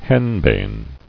[hen·bane]